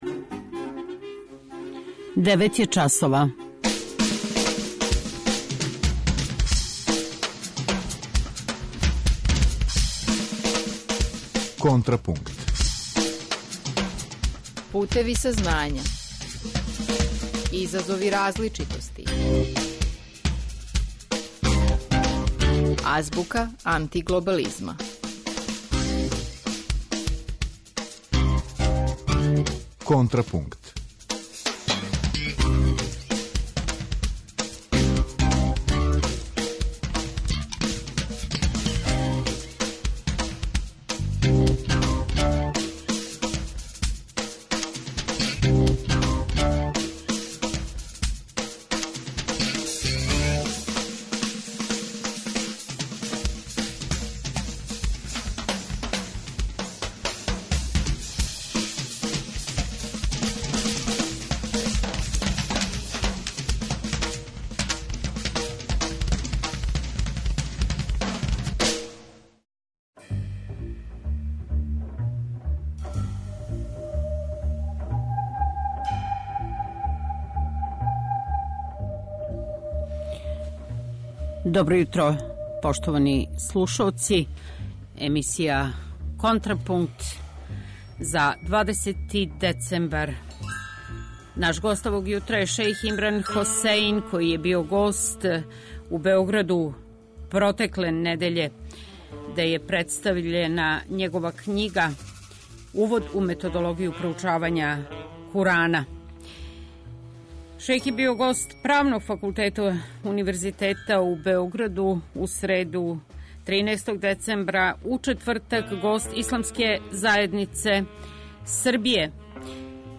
Разговор са шејхом Имраном Хосеином